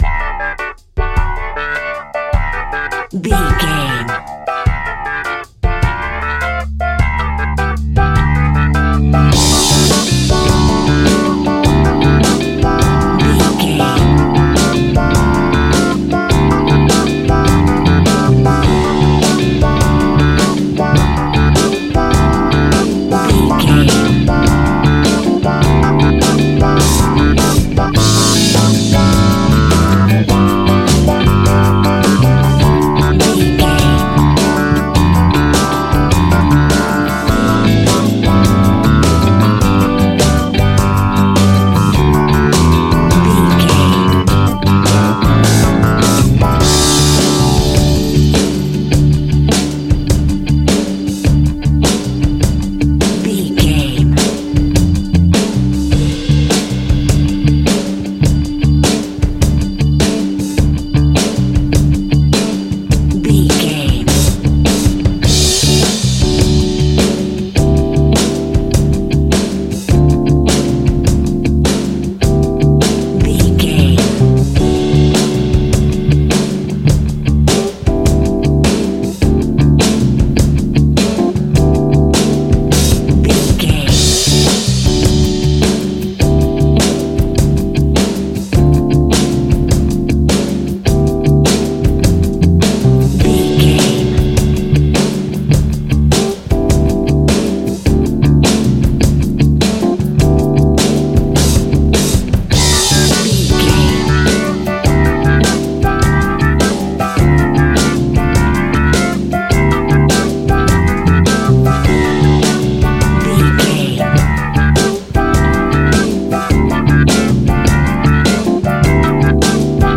Ionian/Major
funky
uplifting
bass guitar
electric guitar
organ
drums
saxophone